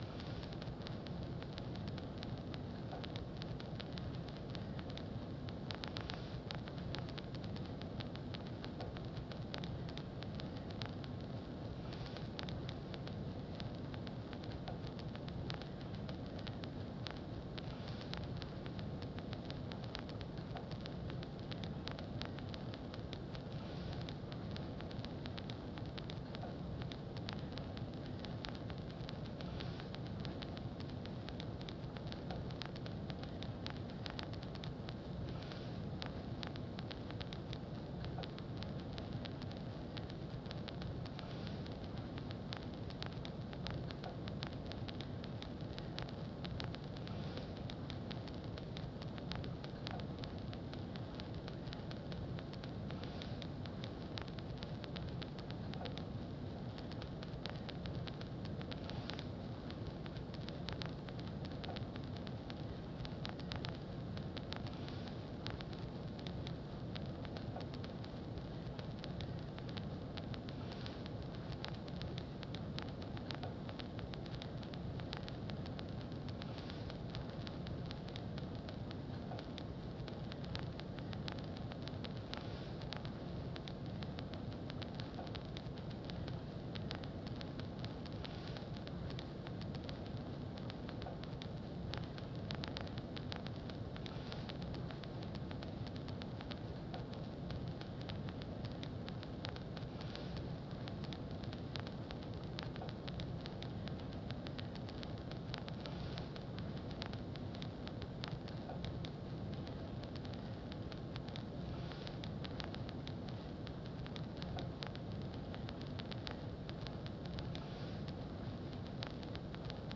This is a recording of the low-level boiler drone which was present in the rooms where some of the interviews for this project were conducted. This low-level sound was treated with post-production processing to make it resemble an aged record, whilst retaining the ambient sound of the room. It was used to create subtle fade in and fade-outs when the voices were being edited, as a sort of sonic underlay in the mix to "patch" sudden cuts or edits.